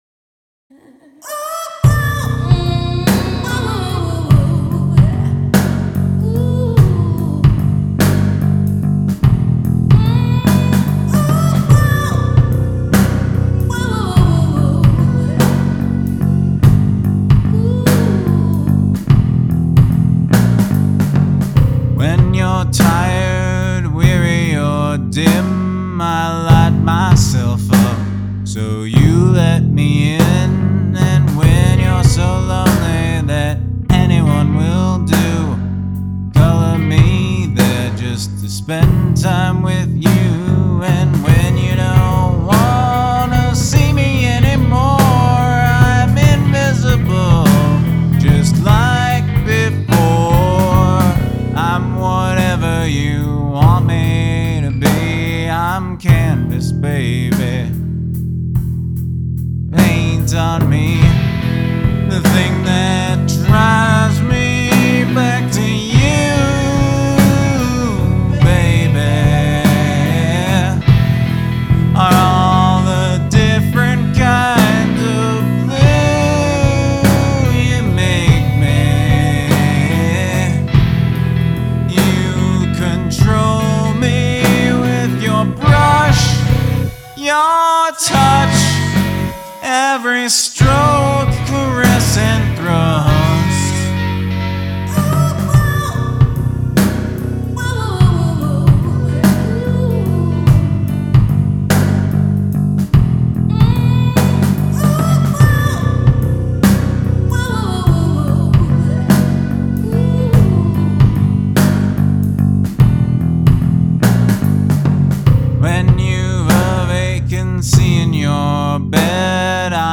Sampled Vocal Hook